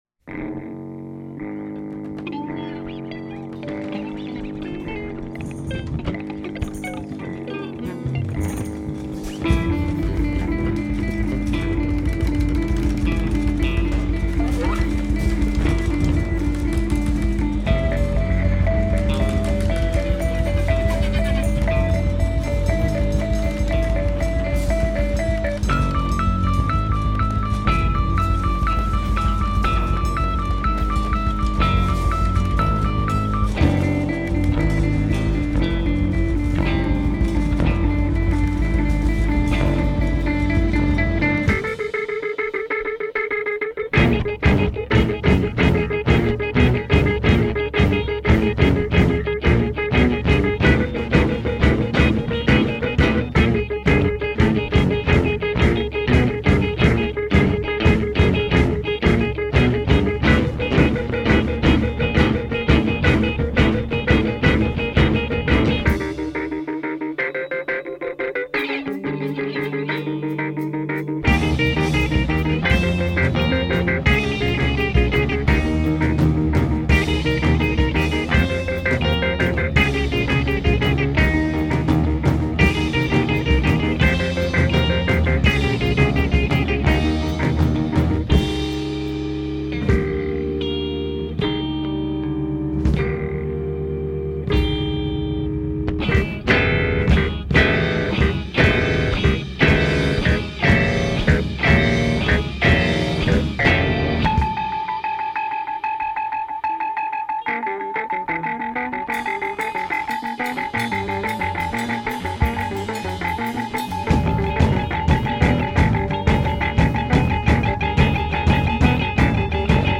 rhodes, drums, guitars, samples
bass, guitar
vocals